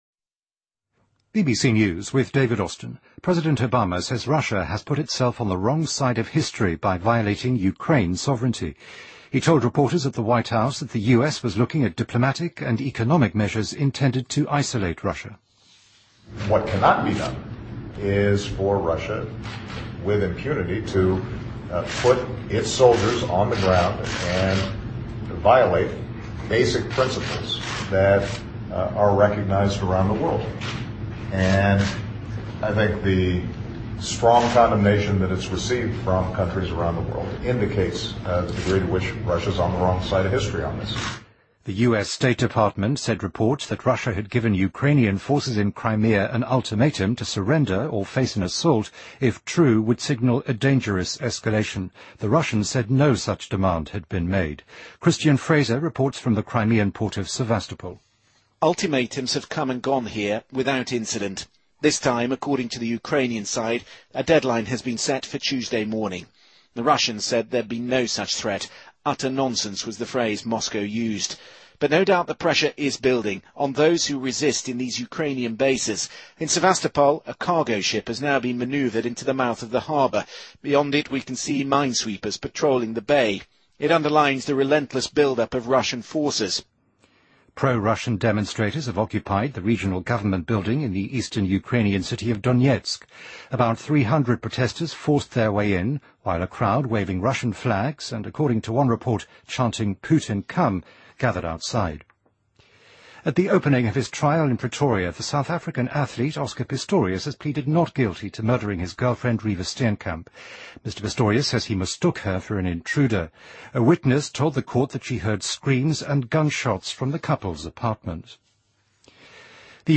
BBC news,2014-03-04